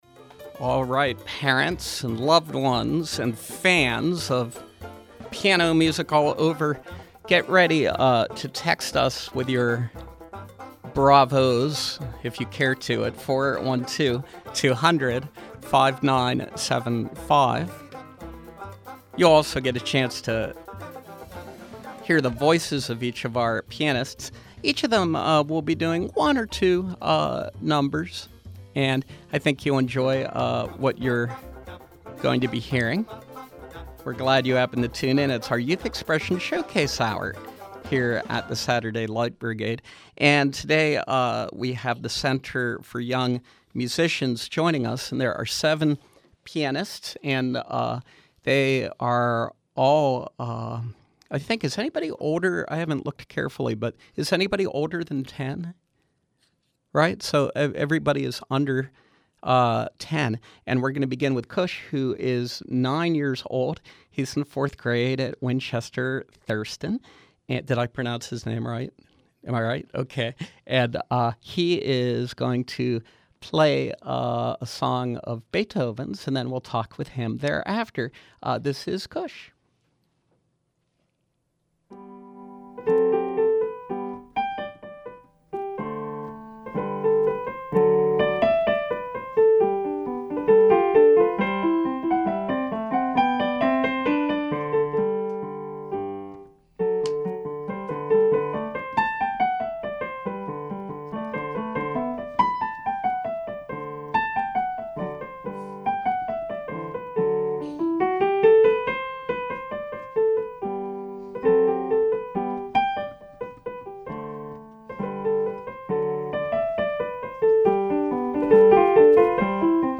Youth pianists from the Center for Young Musicians
playing different pieces of music on piano.